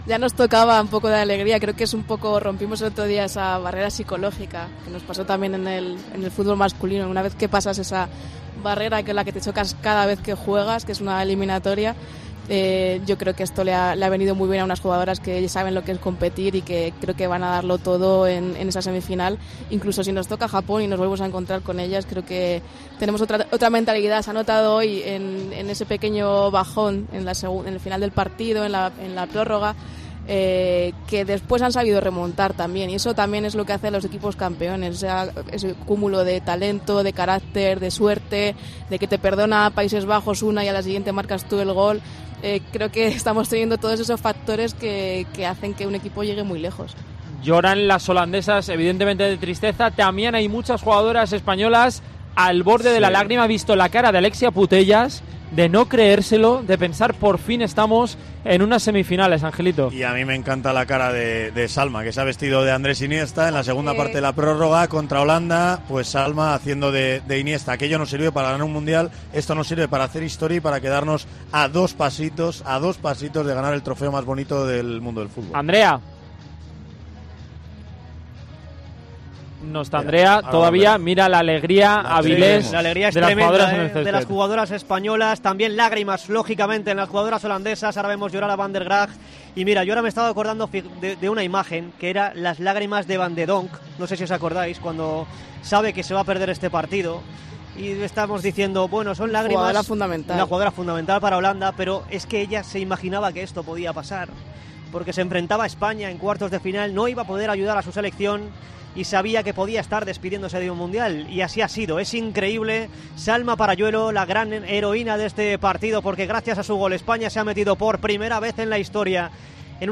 El análisis de los comentaristas de Tiempo de Juego a la histórica clasificación de España